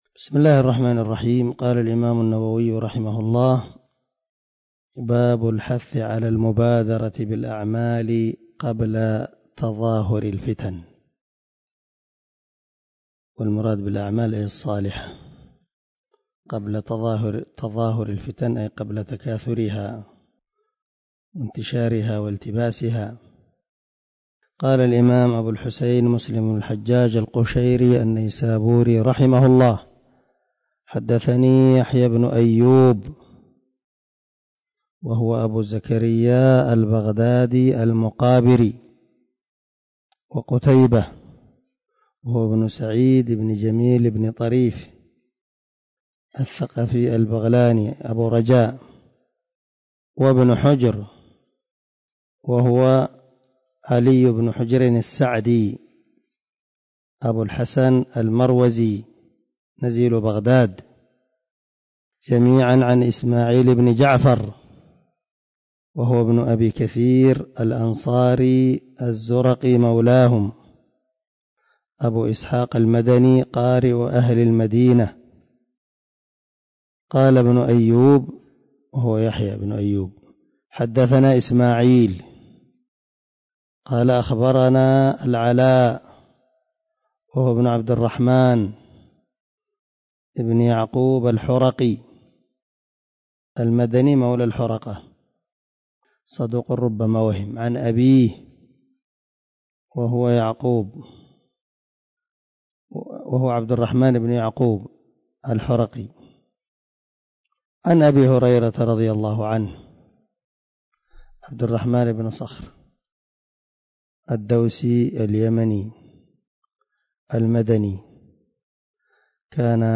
085الدرس 84 من شرح كتاب الإيمان حديث رقم ( 118 ) من صحيح مسلم